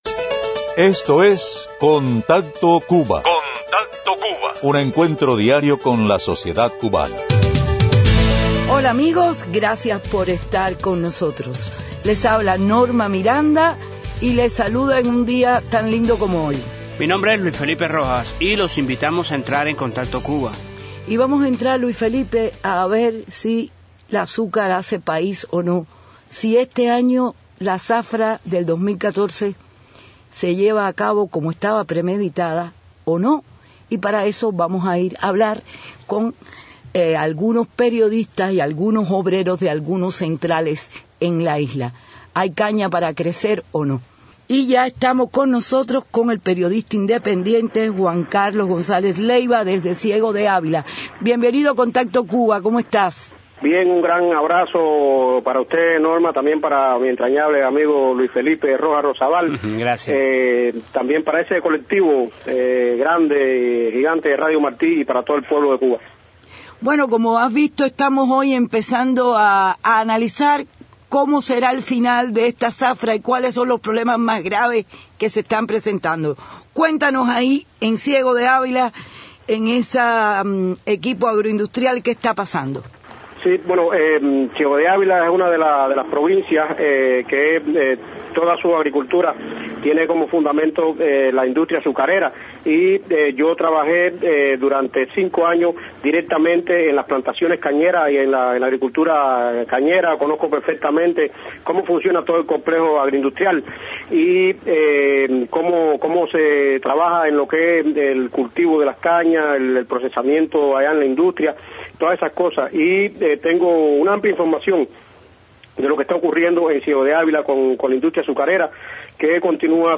Desde la isla, varios opositores que han laborado en la industria azucarera, comentan e informan sobre las condiciones acutales de la zafra azucarera.